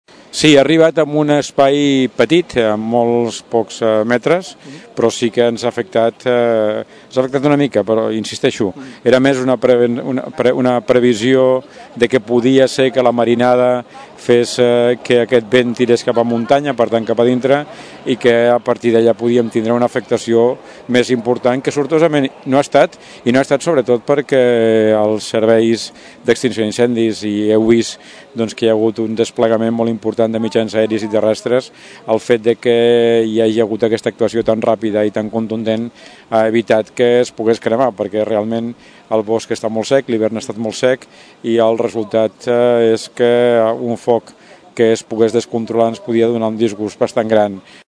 En declaracions a Ràdio Tordera, l’Alcalde del nostre municipi, Joan Carles Gracia, explicava que podem estar satisfets que l’incendi hagi cremat molts pocs metres de terme municipal de Tordera i agraïa la ràpida actuació dels serveis d’emergències.